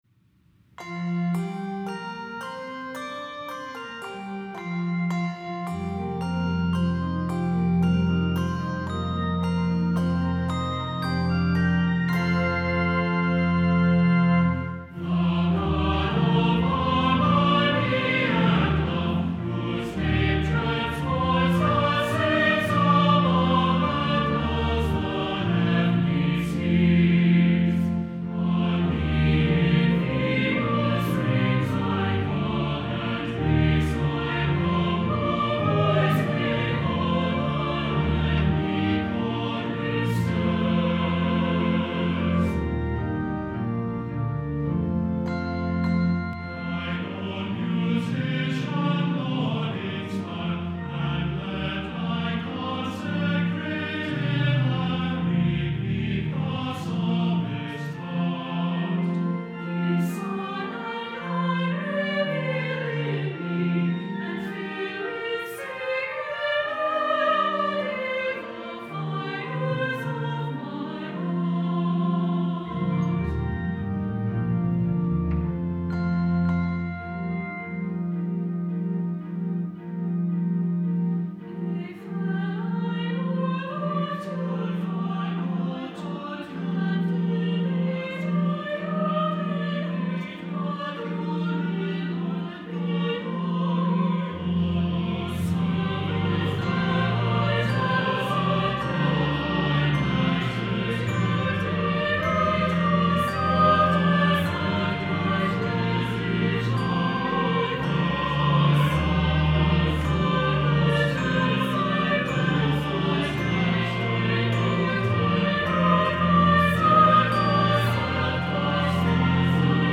this anthem